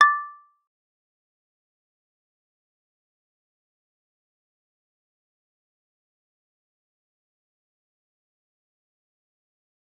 G_Kalimba-D6-f.wav